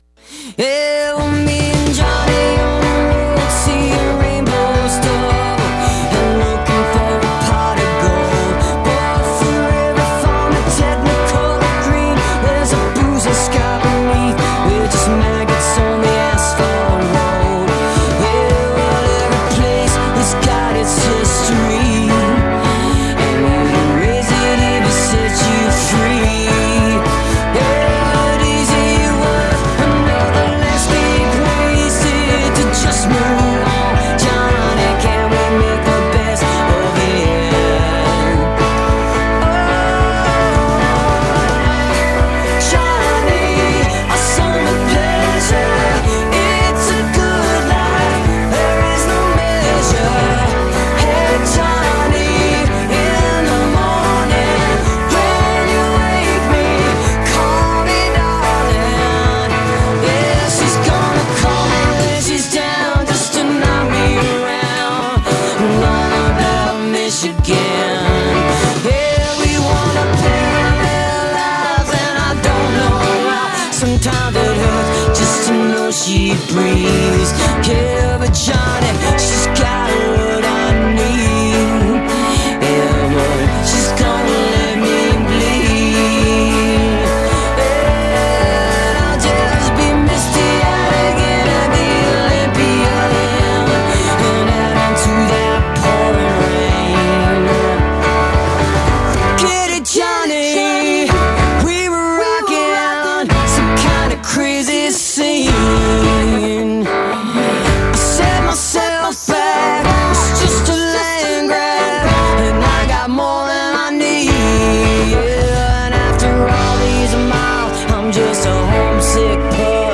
(recorded from webcast)